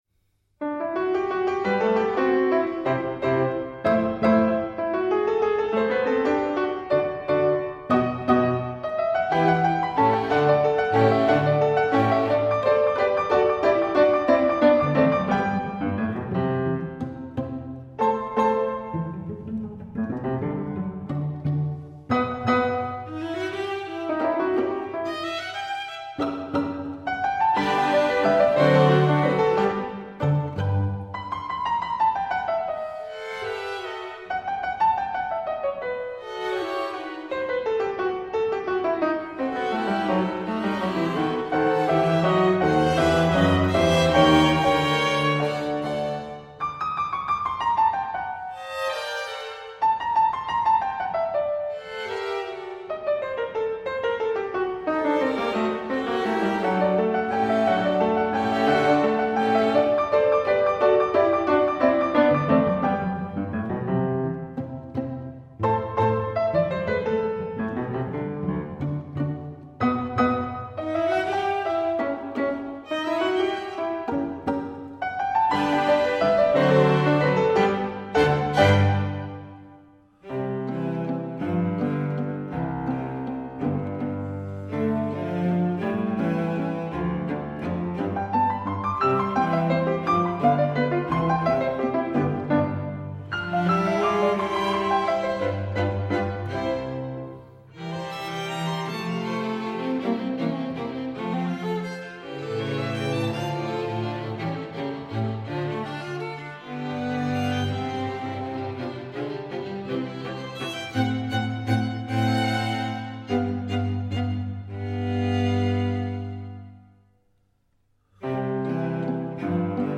Soundbite 3rd Movt